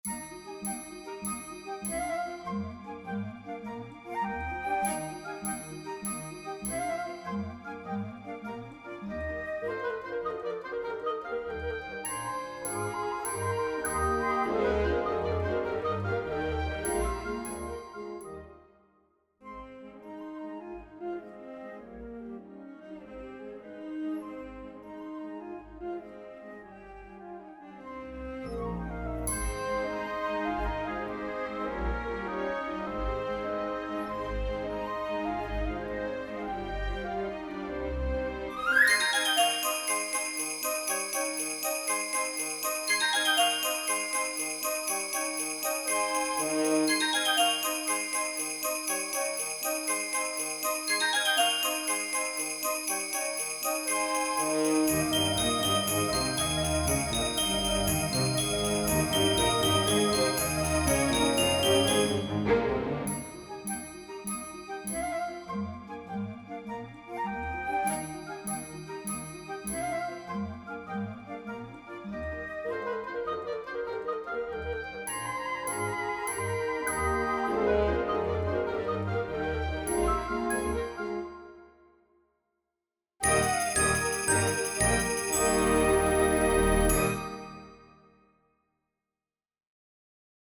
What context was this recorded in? When playing that sound module, you'll hear crappy sounds.